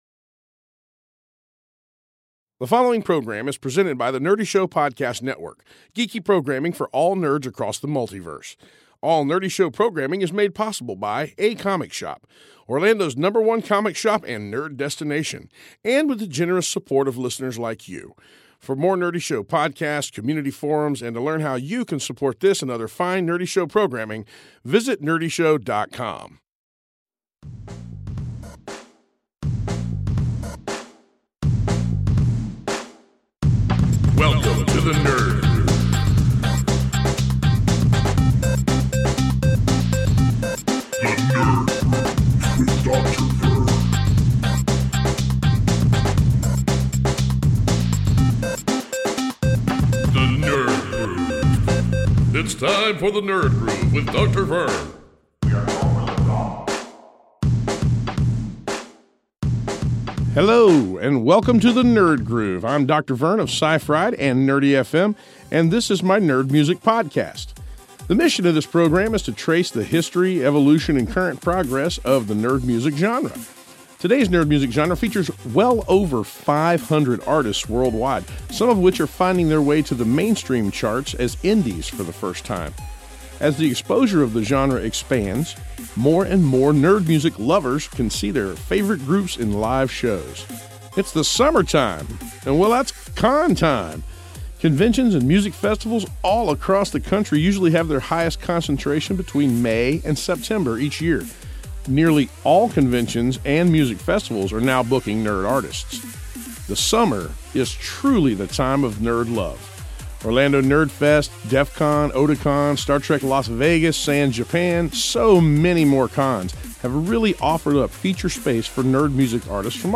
Your monthly fix for Nerd Music is here!